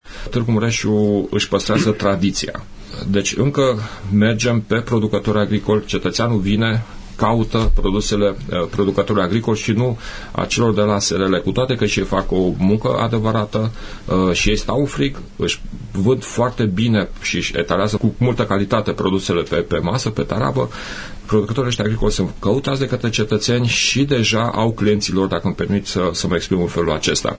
prezent azi la emisiunea „Părerea ta”.